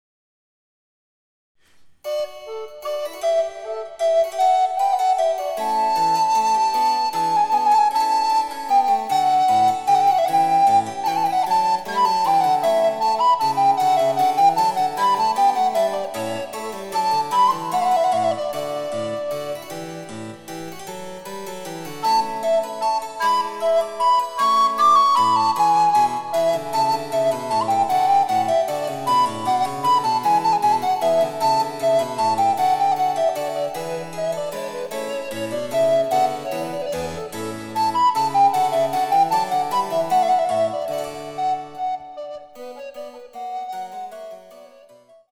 ■リコーダーによる演奏